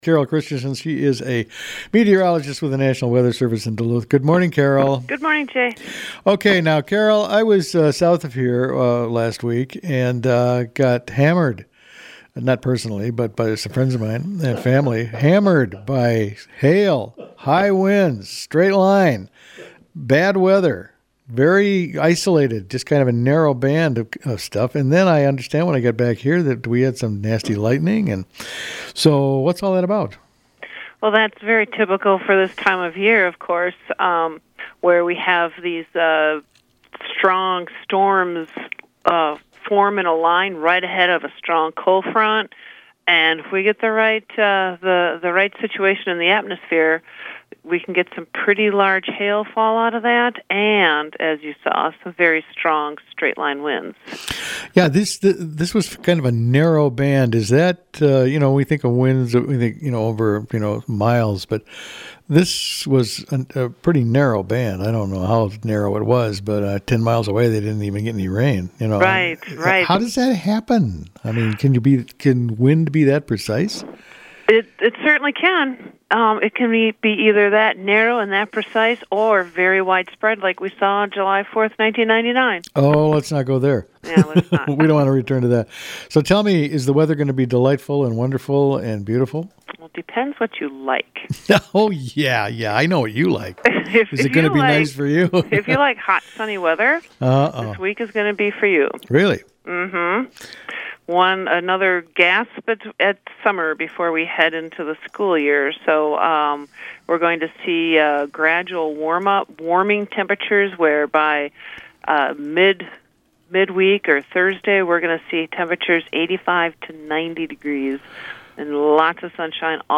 spoke with meteorologist